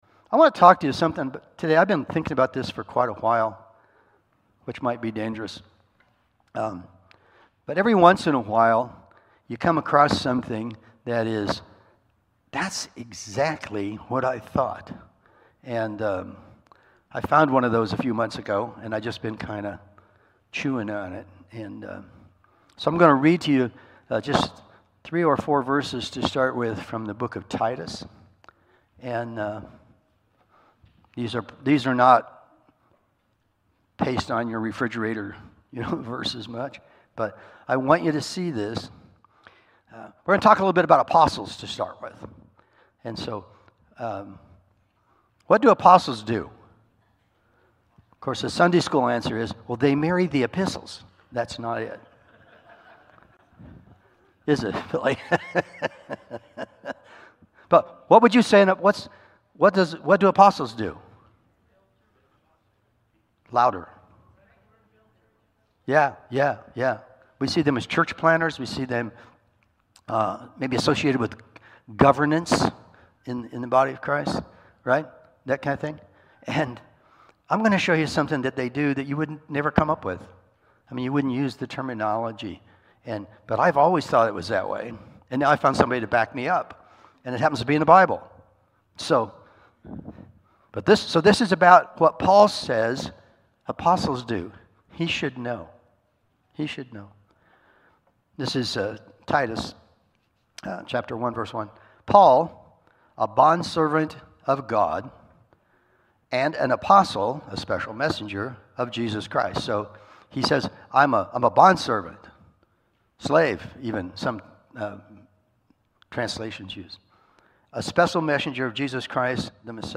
Sunday Morning Sermon Download Files Notes